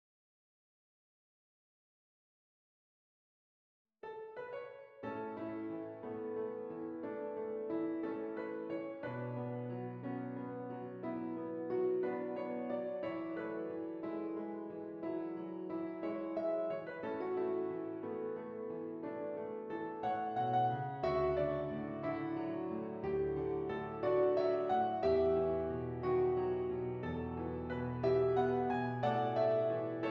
Does Not Contain Lyrics
A Major
Moderately